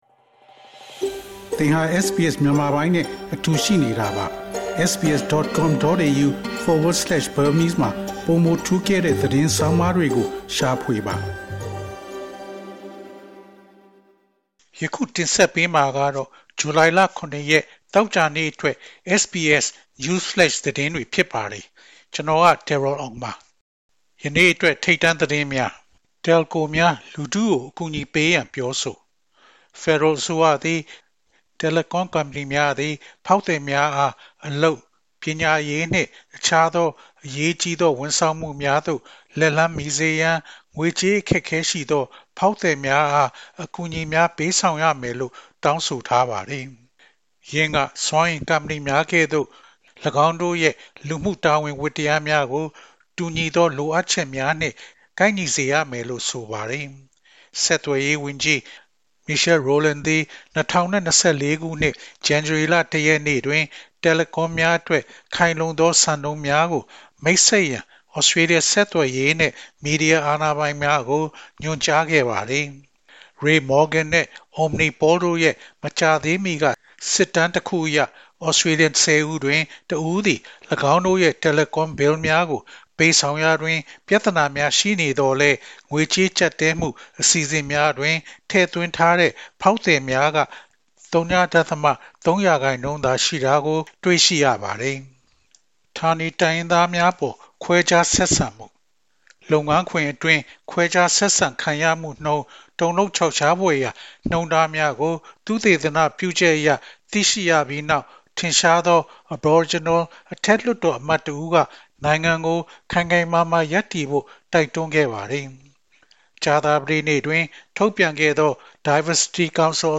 Burmese News Flash